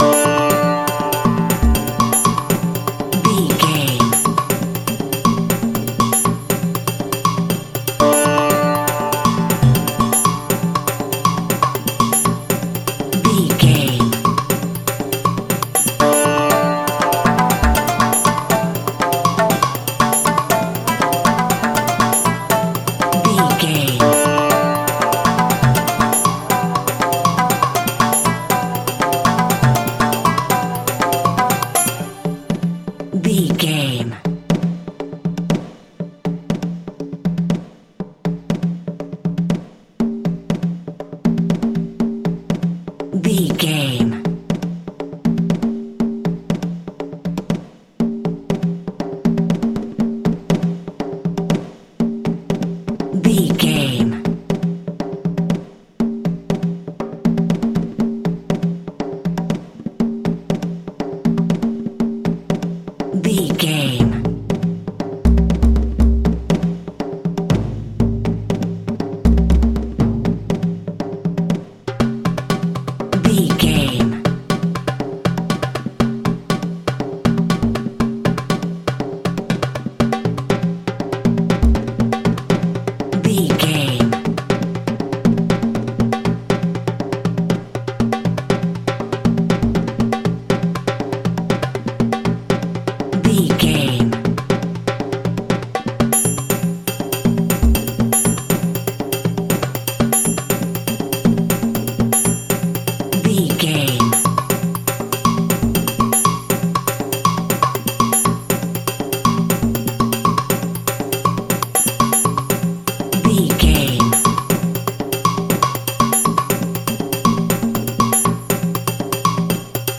Atonal
world beat
ethnic percussion
congas
bongos
taiko drums